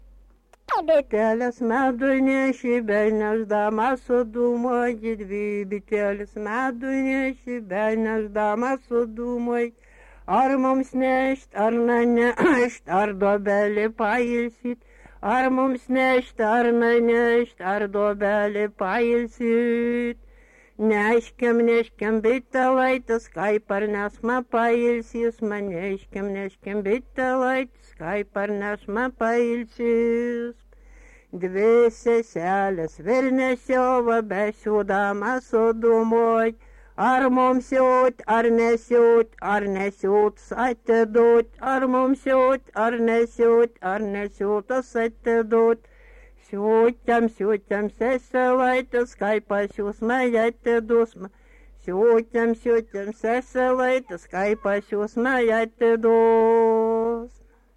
daina
Atlikimo pubūdis vokalinis